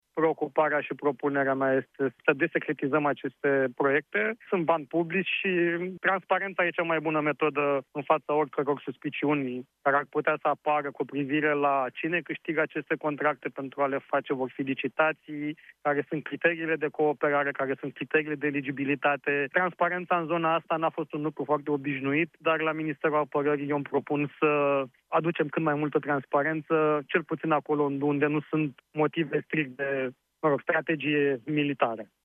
România va cere Comisiei Europene acordul pentru desecretizarea proiectelor ce se vor realiza cu banii primiți prin programul SAFE, pentru înzestrarea Armatei – spune, la Europa FM, ministrul Apărării, Radu Miruță.